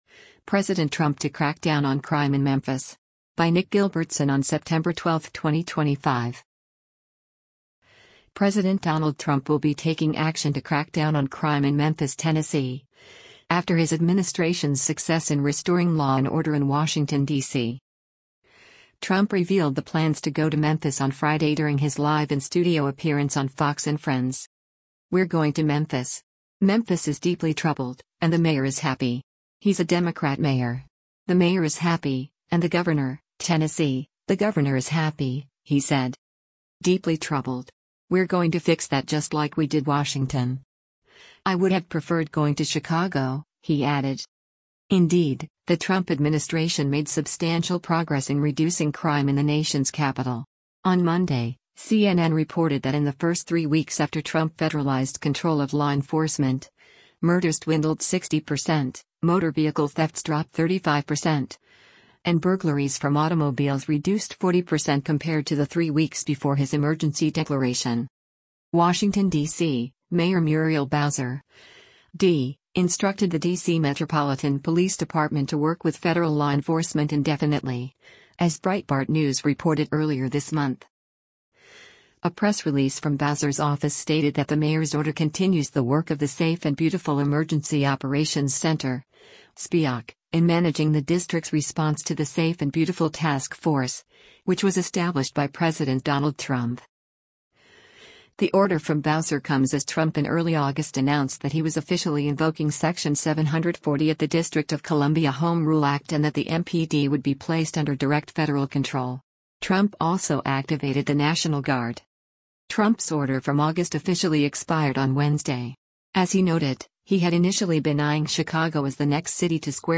Trump revealed the plans to go to Memphis on Friday during his live in-studio appearance on Fox & Friends.